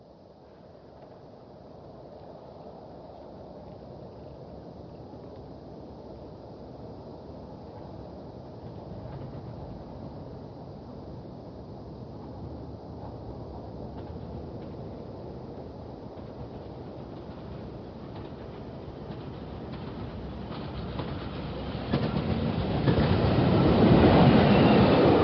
Scottish Diesels class 40 passes N. Queensferry
A class 40 diesel heads a 7 coach train on the 17.09 Edinburgh to Aberdeen at 17.25 through North Queensferry on 12.2.77
Stereo MP3 128kb file